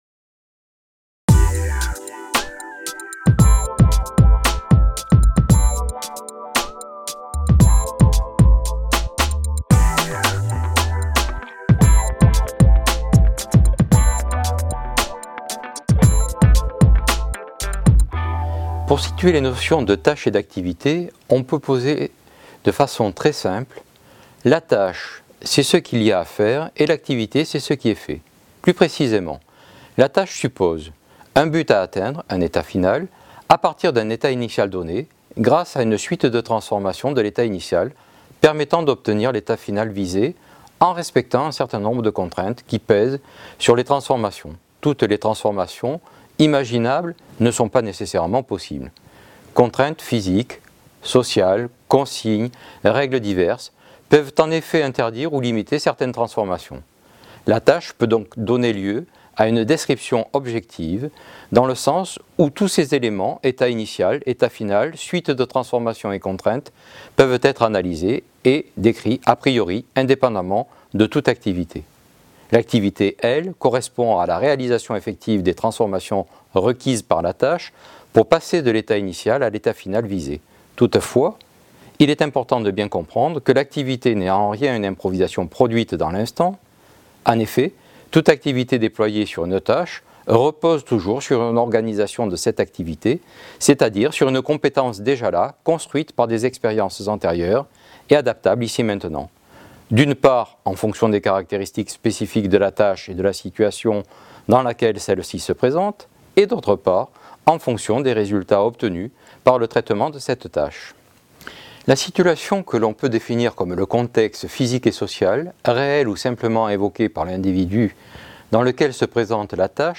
Vidéo pédagogique